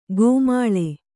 ♪ gōmāḷe